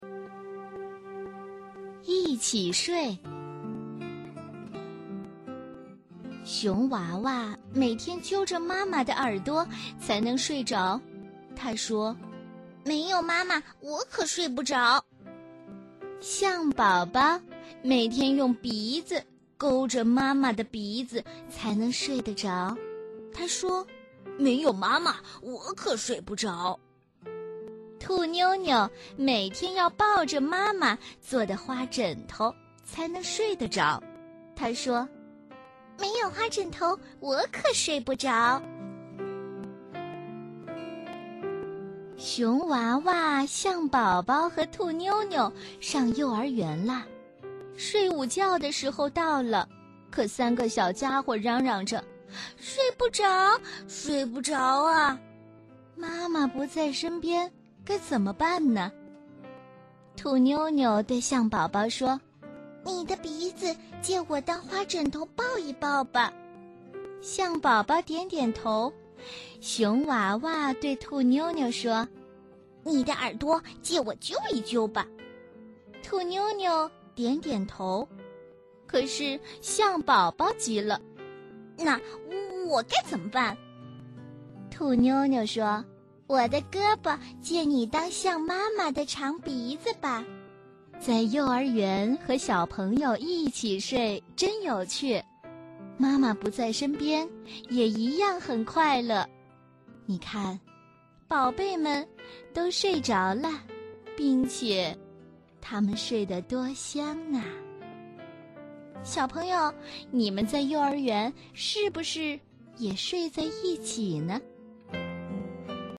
首页>mp3 > 儿童故事 > 一起睡